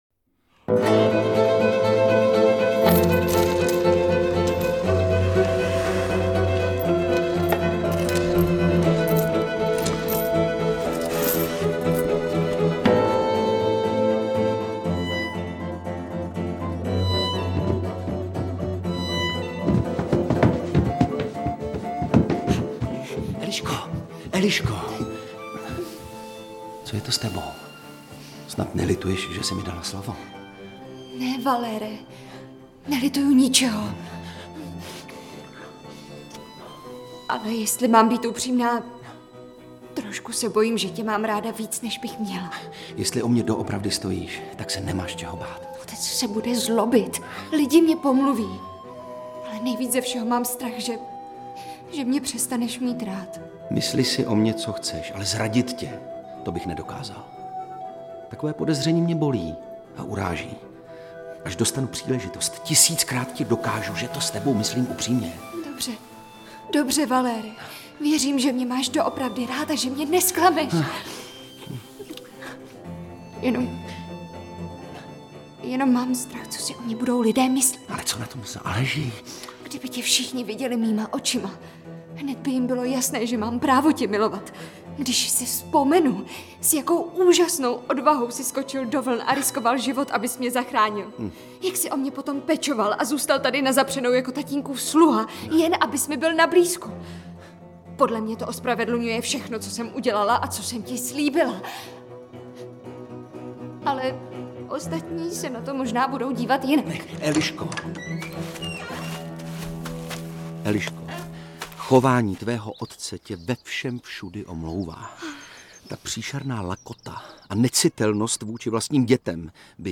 Interpret:  Ivan Trojan
Ivan Trojan v titulní roli slavné komedie, v níž jde o peníze až v první řadě.
AudioKniha ke stažení, 5 x mp3, délka 1 hod. 17 min., velikost 101,3 MB, česky